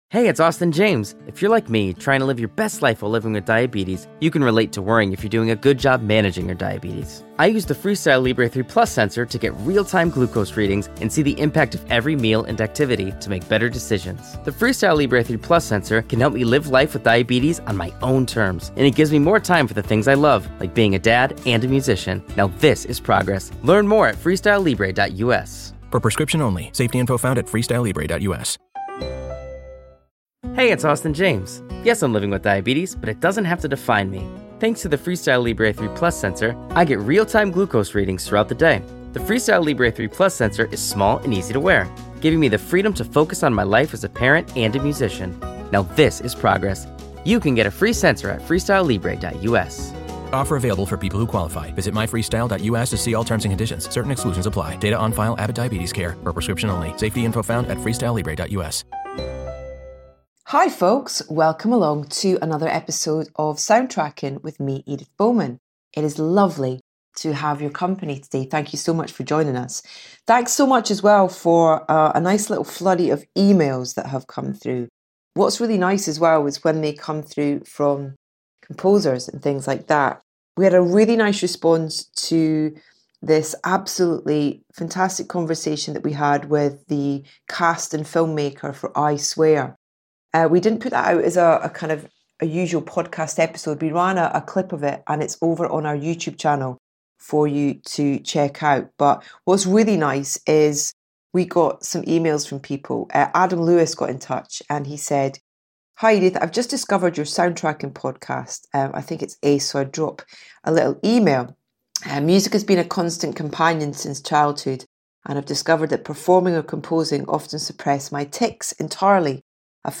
Our latest guest on Soundtracking is Aziz Ansari, who joins Edith live in front of an audience at Whiteley's Everyman for one of our Everyman Soundtracking Film clubs following a screening of his film, Good Fortune, which he wrote, produced, directed AND appears in.